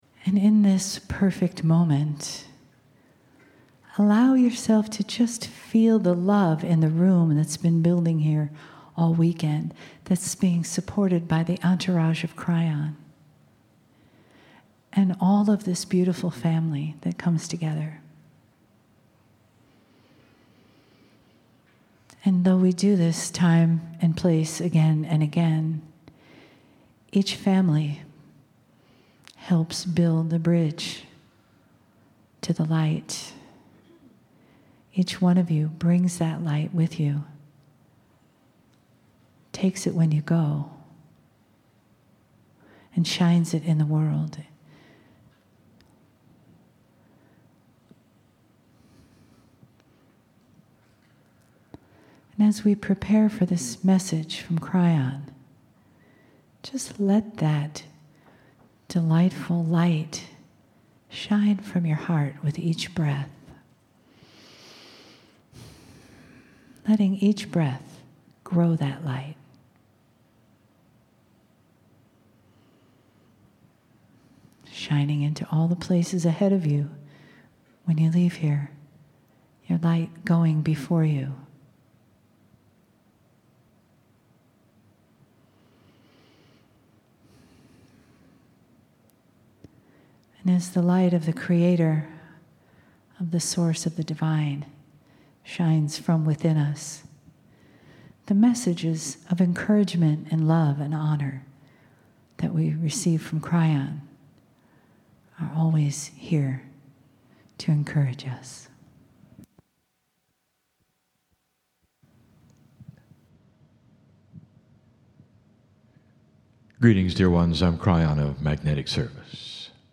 en Grand Rapids, Michigan, el 17 de julio de 2016